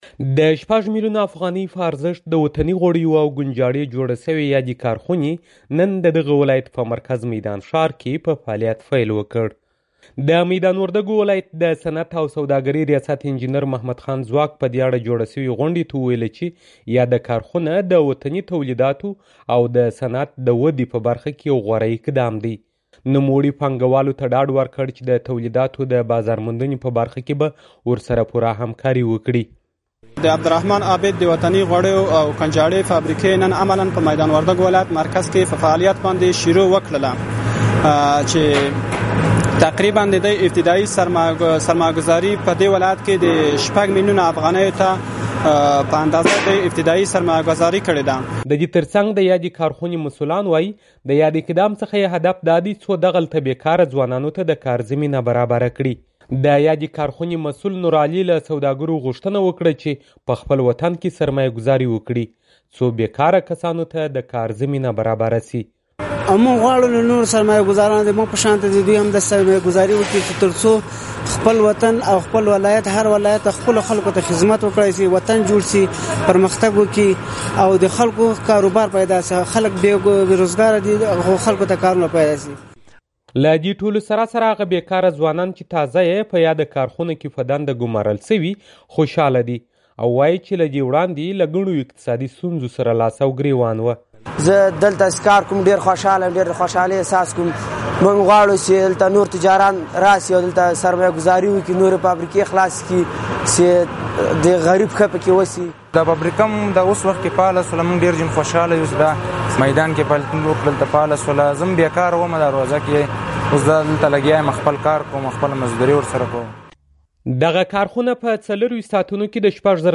د میدان وردګ راپور